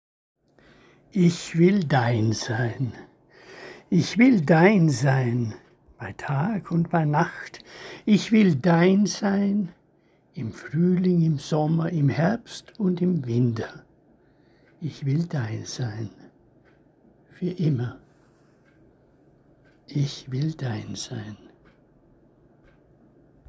Lesung eigener Gedichte